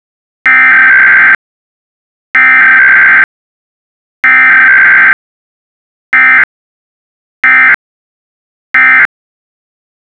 eas-same-encoder - A Python script that generates valid EAS SAME messages.